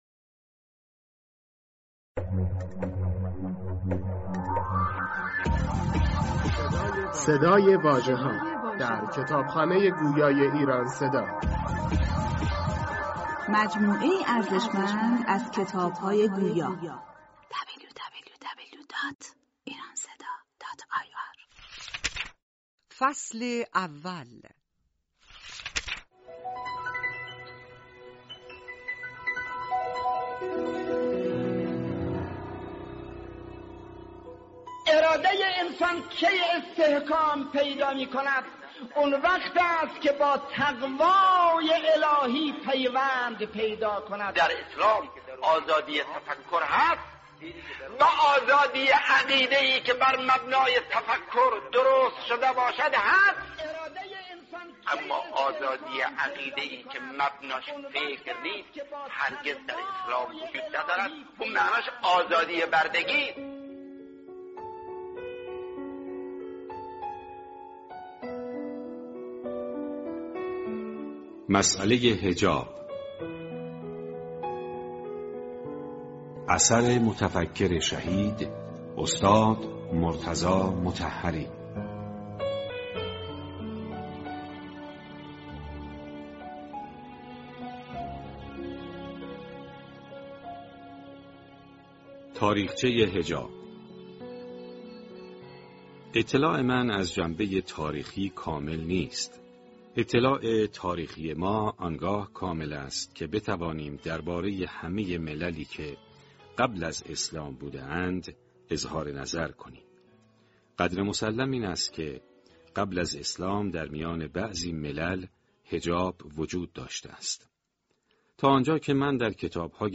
مسئله حجاب 🎙کتاب صوتی